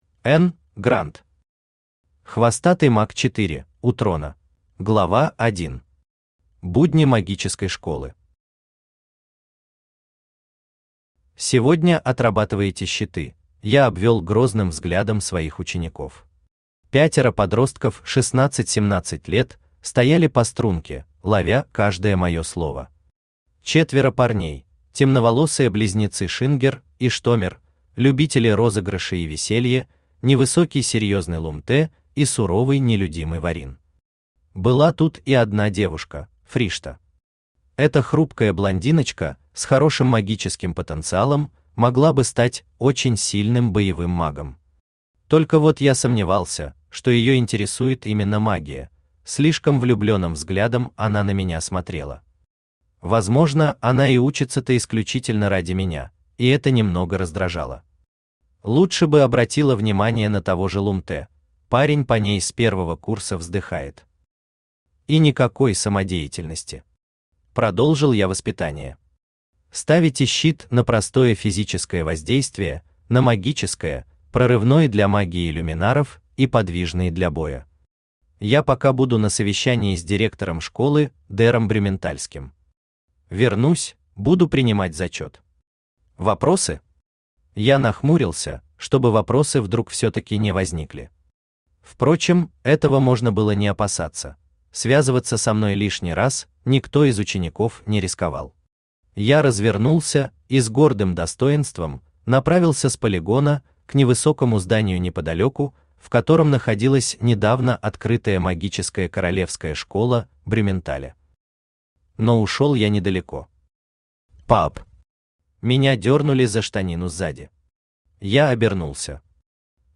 Аудиокнига Хвостатый маг 4: у трона | Библиотека аудиокниг
Aудиокнига Хвостатый маг 4: у трона Автор Н. Гранд Читает аудиокнигу Авточтец ЛитРес.